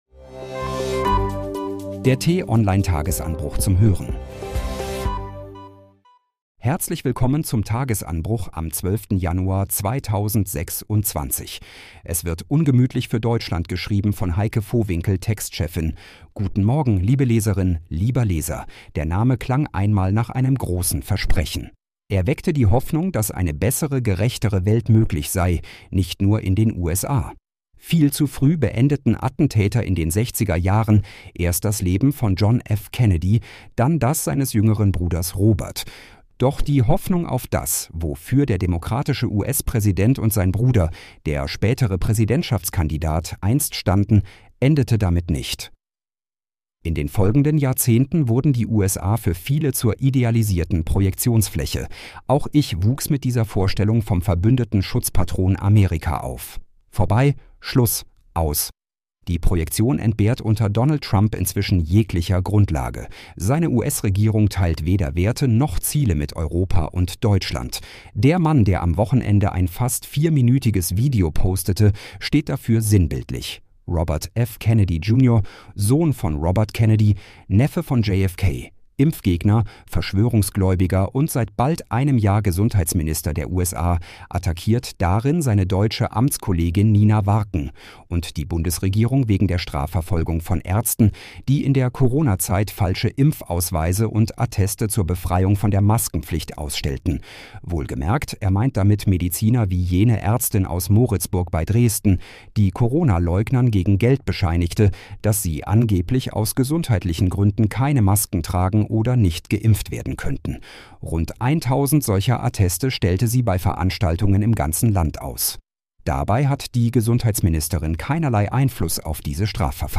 zum Start in den Tag vorgelesen von einer freundlichen KI-Stimme –